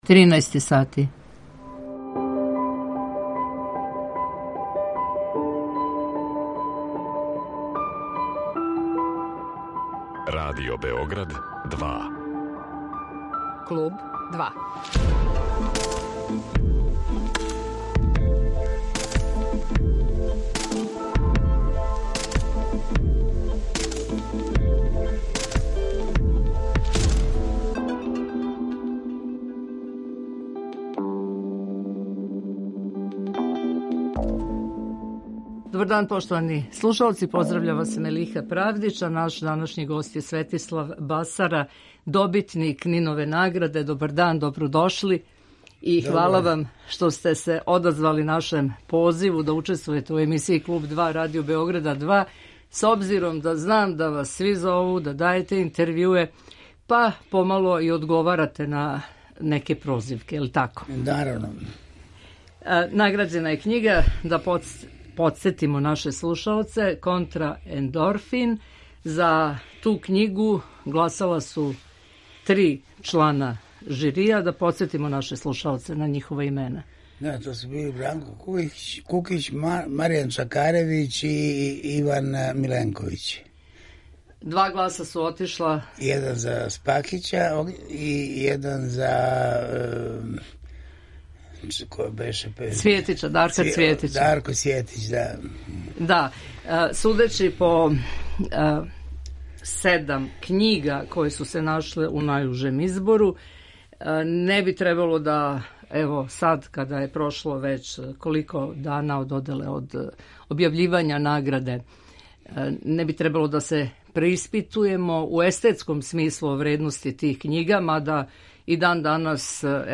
Гост Клуба 2 је Светислав Басара, добитник Нинове награде за књигу „Контраендорфин'